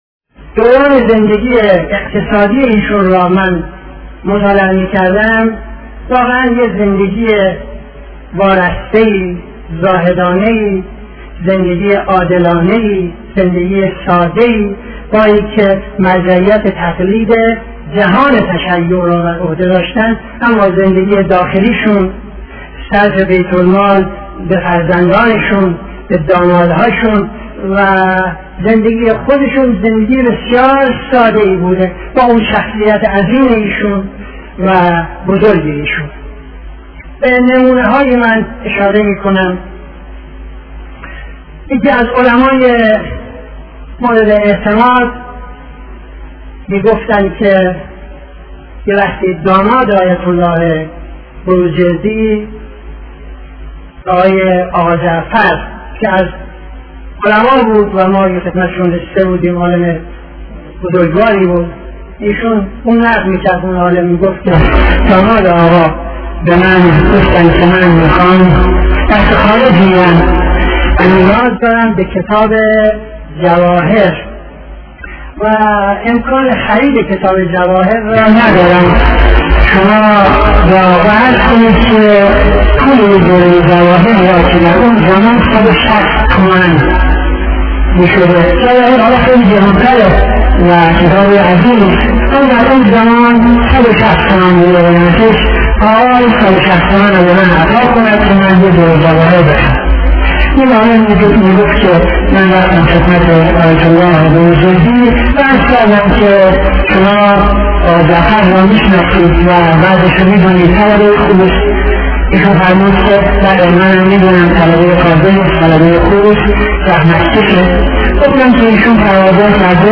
خطبه اول نماز جمعه 30-09-75